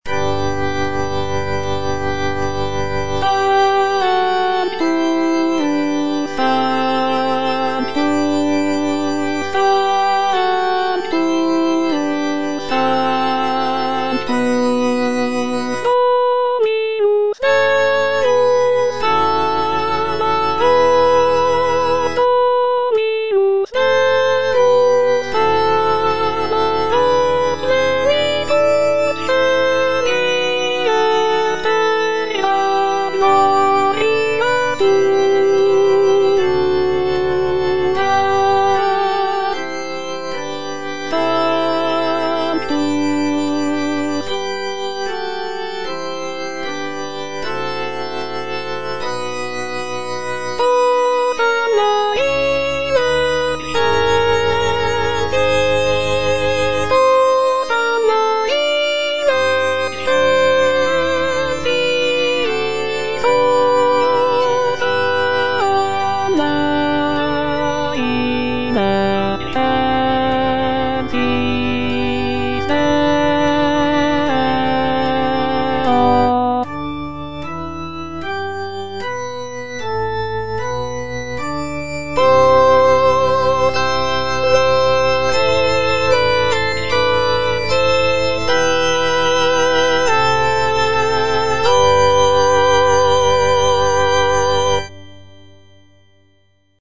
G. FAURÉ, A. MESSAGER - MESSE DES PÊCHEURS DE VILLERVILLE Sanctus - Alto (Voice with metronome) Ads stop: auto-stop